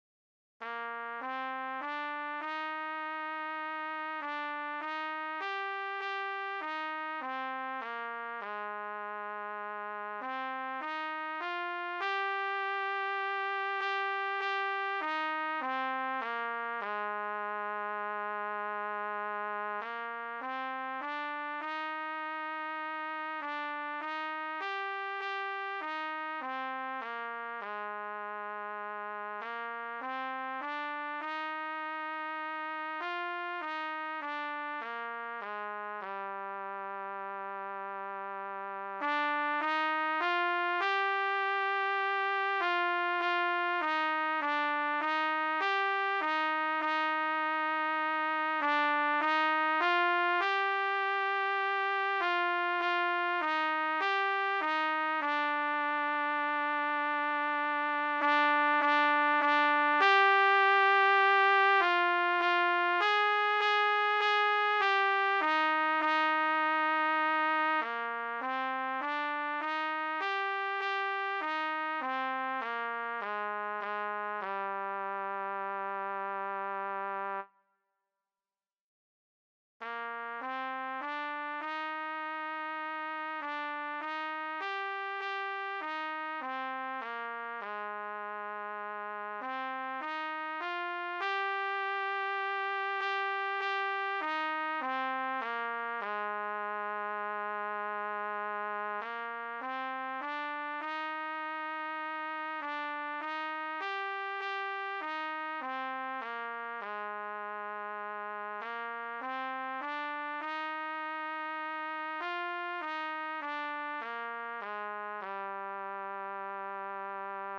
B=Harmony-for intermediate players